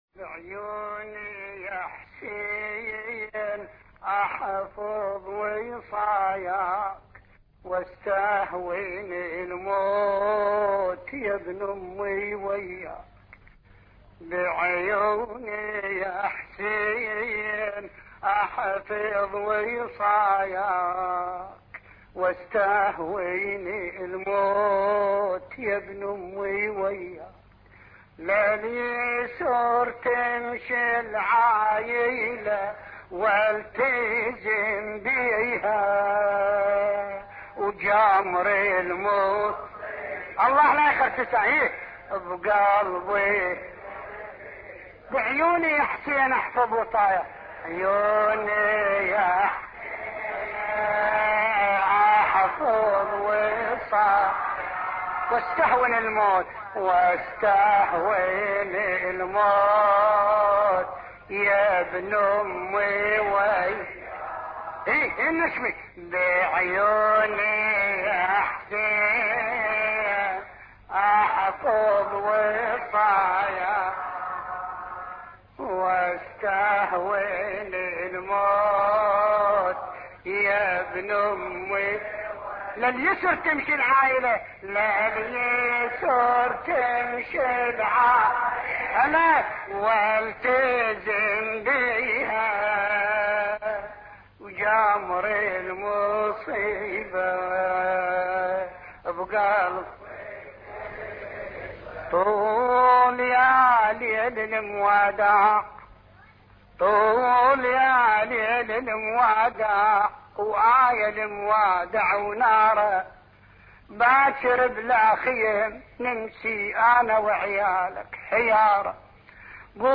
مراثي الامام الحسين (ع)